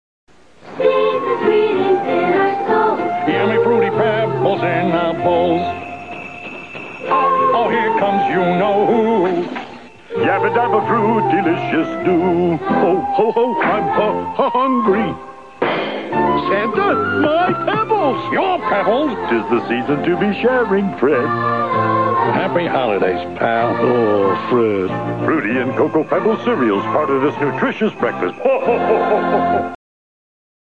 here’s a more “traditional” holiday song that many are sure to know and enjoy.
xmas_song.mp3